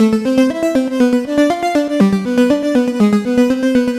Index of /musicradar/french-house-chillout-samples/120bpm/Instruments
FHC_Arp B_120-A.wav